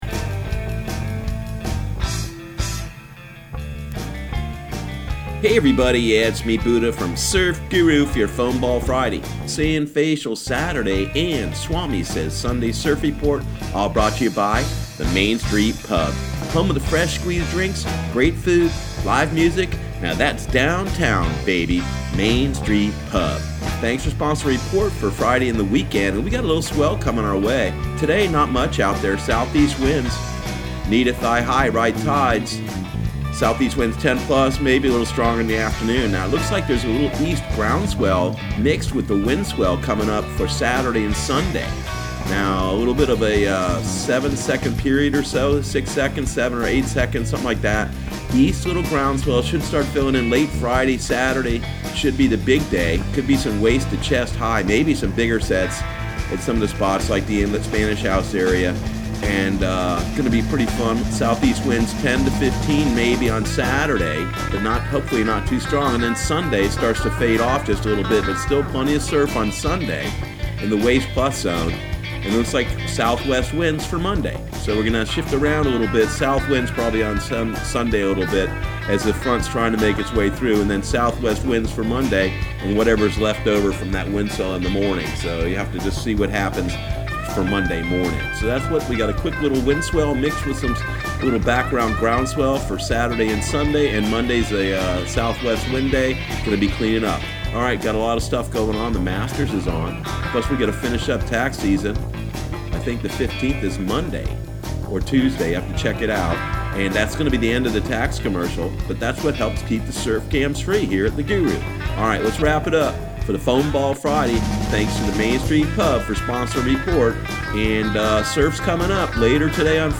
Surf Guru Surf Report and Forecast 04/12/2019 Audio surf report and surf forecast on April 12 for Central Florida and the Southeast.